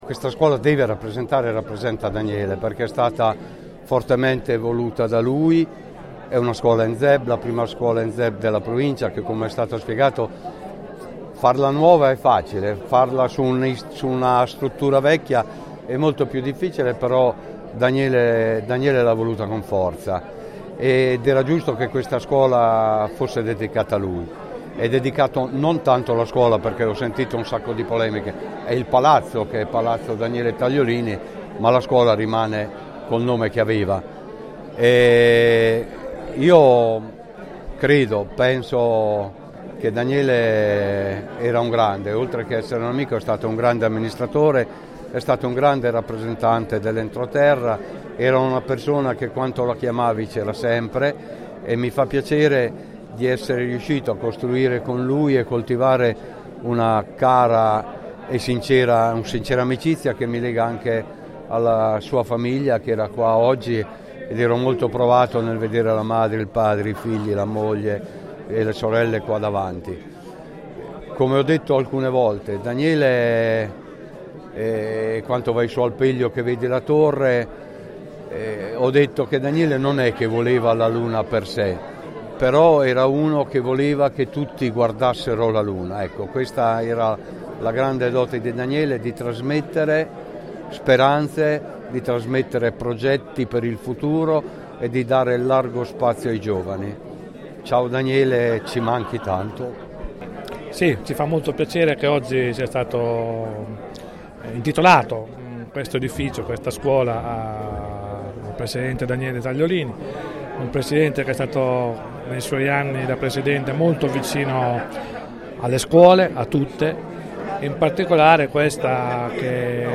Ai nostri Microfoni: Giuseppe Paolini, Presidente della Provincia di Pesaro e Urbino